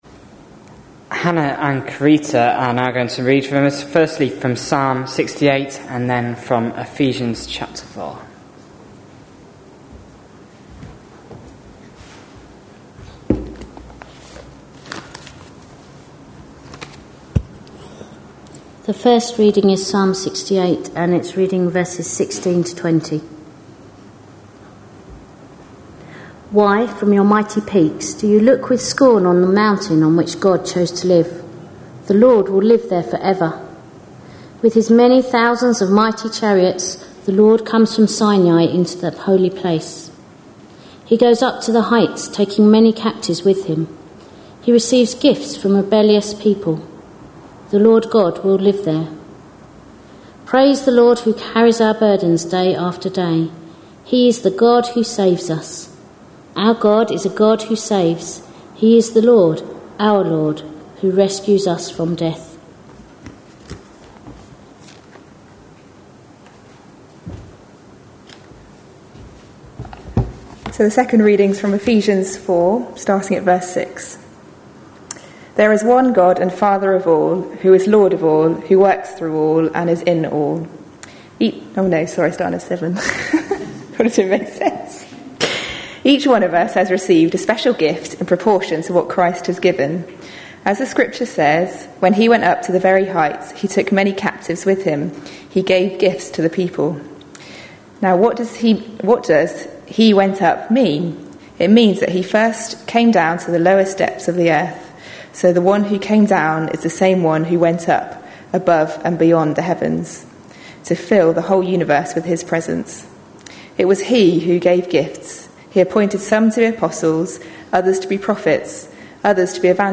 A sermon preached on 24th June, 2012, as part of our A Purple Chapter series.